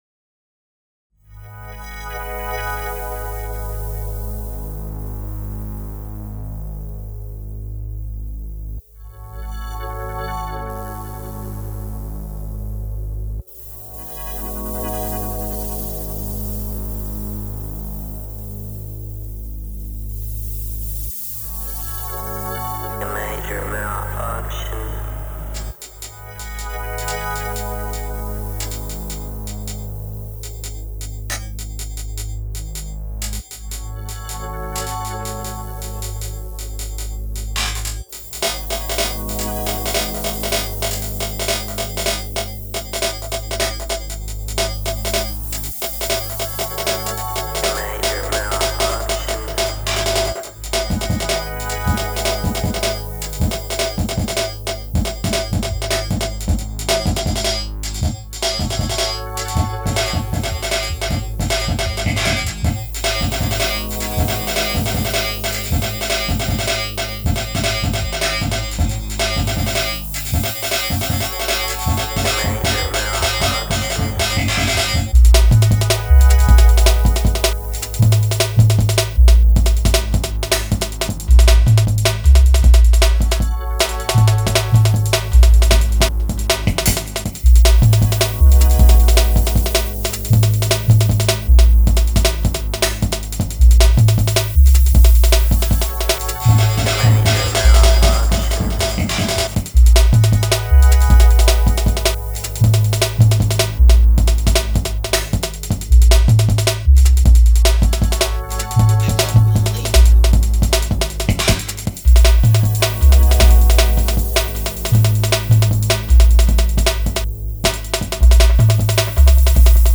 Genre Jungle